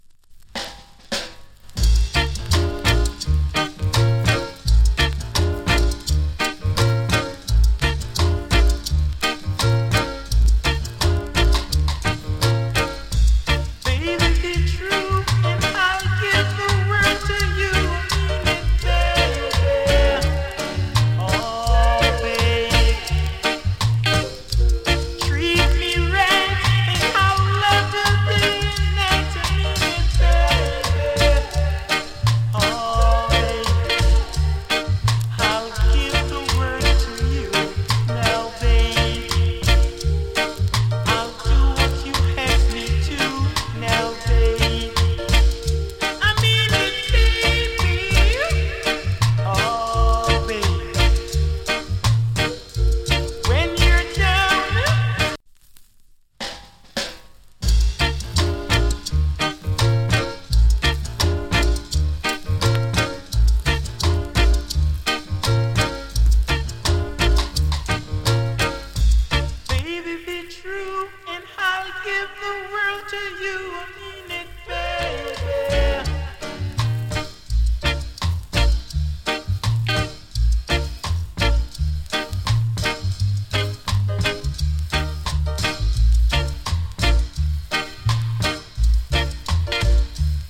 チリ、パチノイズ少し有り。
MIX 違い VOCAL TAKE ＋ DUB VER.